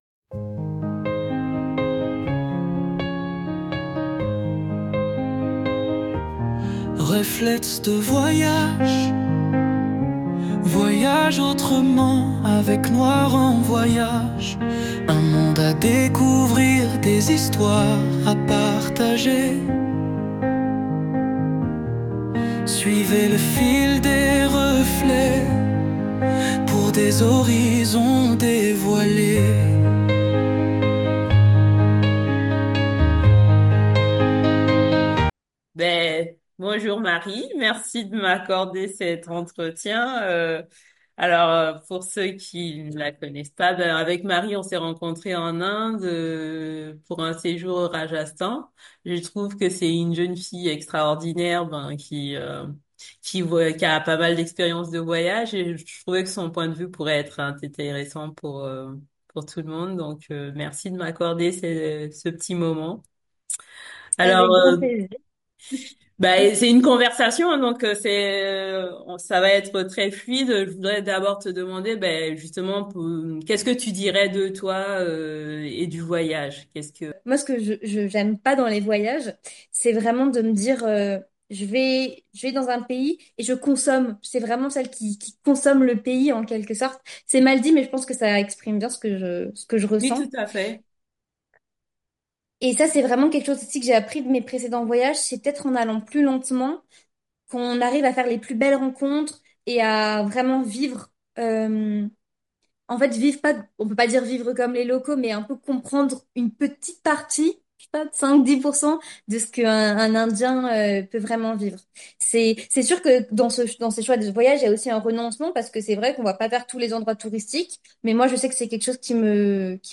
À travers cette interview, elle partage son parcours, ses expériences et sa vision du voyage.